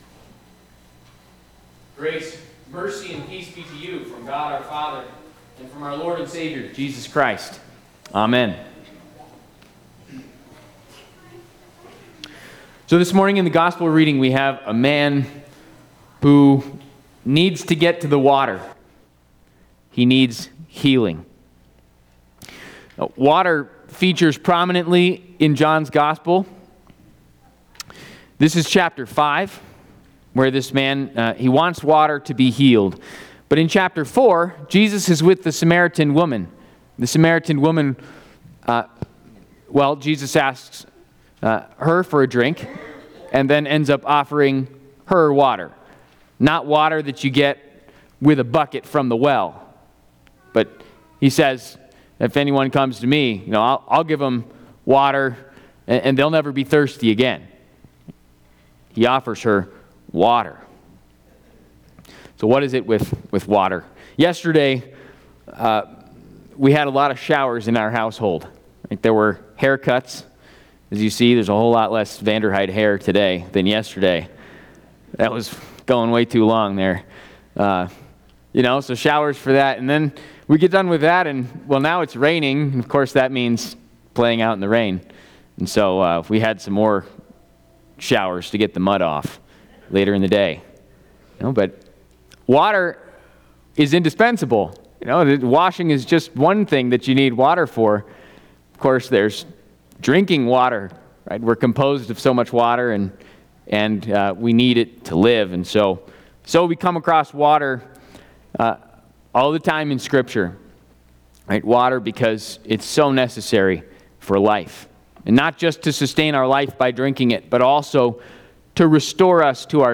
Sixth Sunday of Easter&nbsp